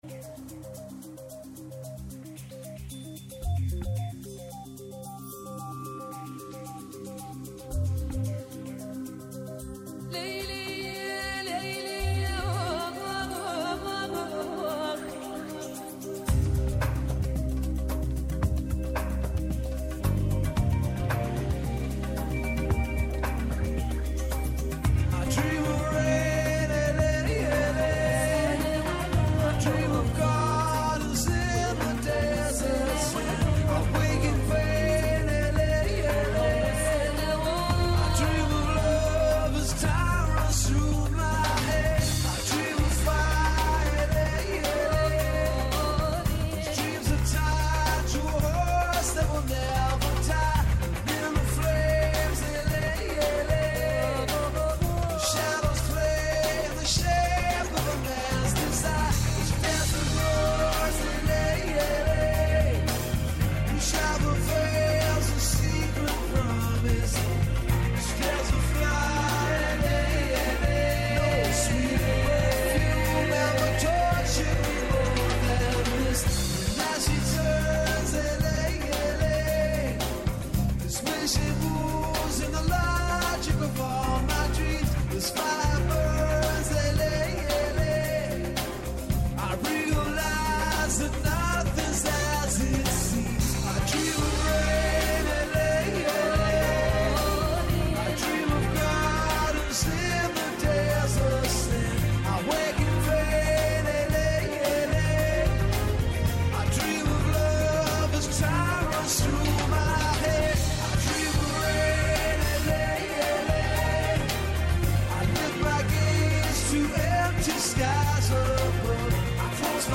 Αποχαιρετά την ημέρα που τελειώνει, ανιχνεύοντας αυτή που έρχεται. Διεθνή και εγχώρια επικαιρότητα, πολιτισμός, πρόσωπα, ιστορίες αλλά και αποτύπωση της ατζέντας της επόμενης ημέρας συνθέτουν ένα διαφορετικό είδος μαγκαζίνου με στόχο να εντοπίσουμε το θέμα της επόμενης ημέρας.